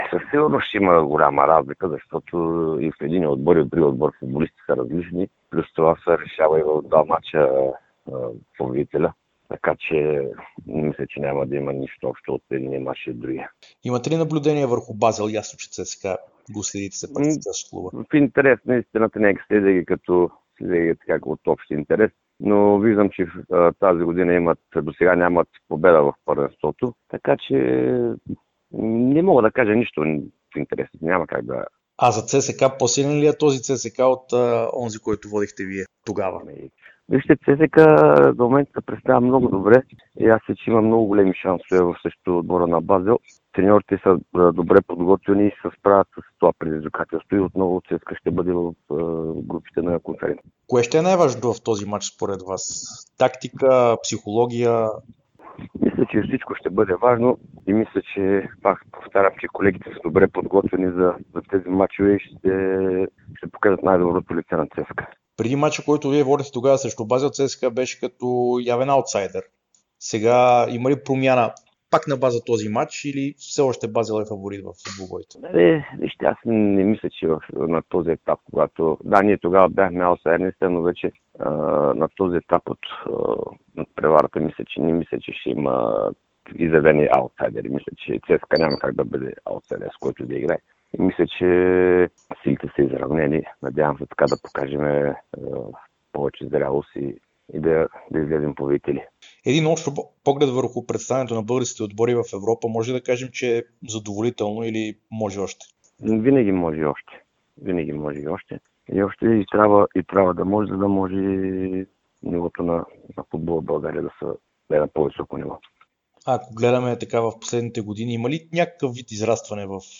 ексклузивно интервю пред Дарик радио и dsport